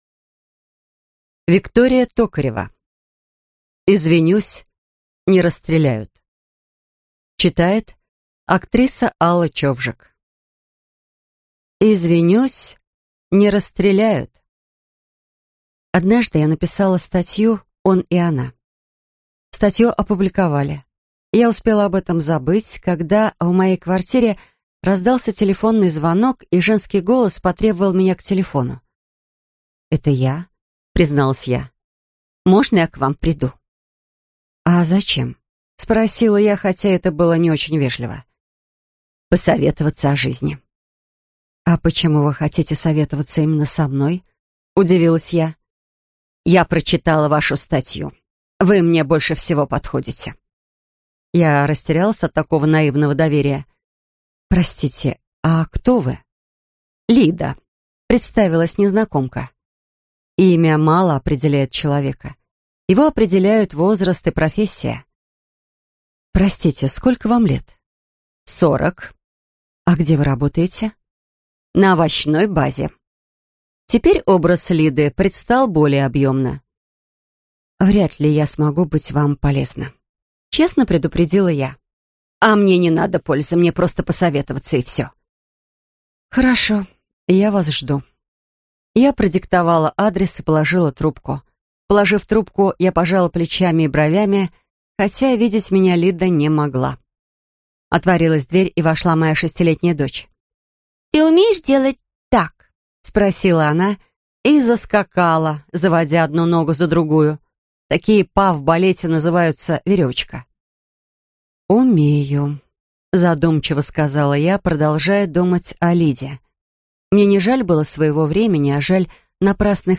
Аудиокнига Извинюсь. Не расстреляют (сборник) | Библиотека аудиокниг